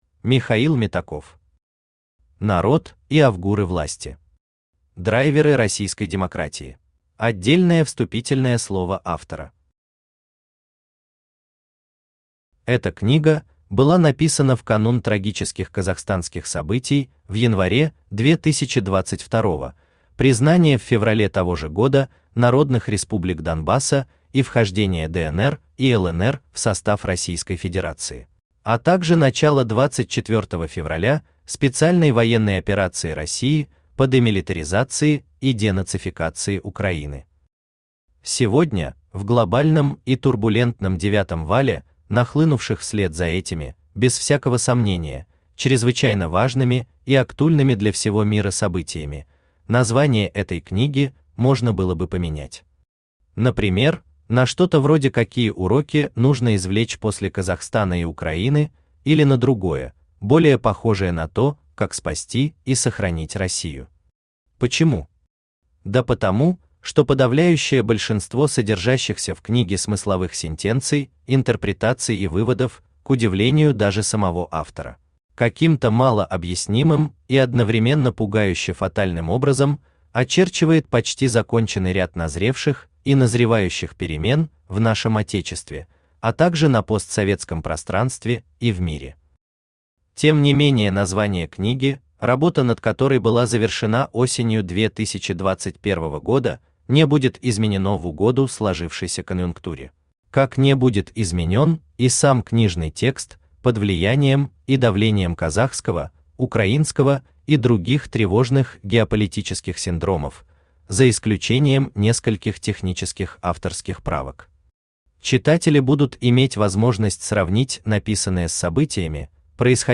Аудиокнига Народ и авгуры власти.
Aудиокнига Народ и авгуры власти. Драйверы российской демократии Автор Михаил Митрофанович Метаков Читает аудиокнигу Авточтец ЛитРес.